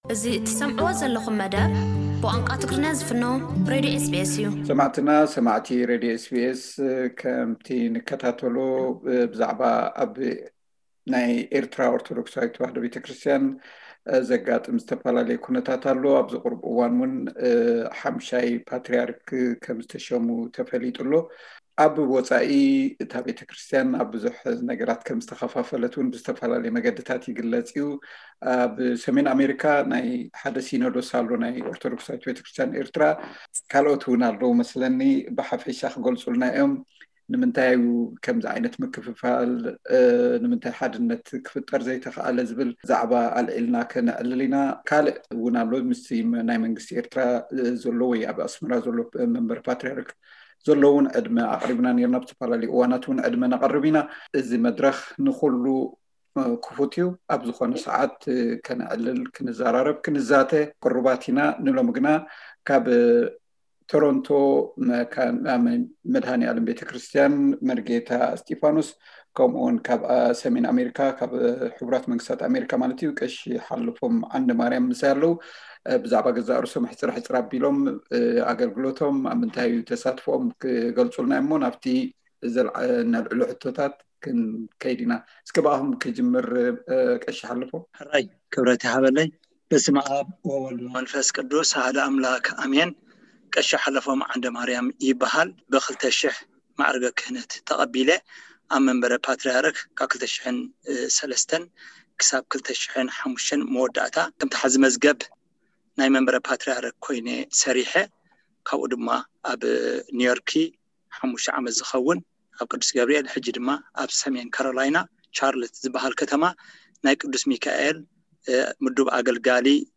ዘተ ምስ ኣብ ትሕቲ ኣቡነ እንጦንዮስ ዘጽልሉ ግና ኣብ ዝተፈላለዩ ጉጅለ ቤተ ክህነት ዘለዉ መራሕቲ ሃይማኖት።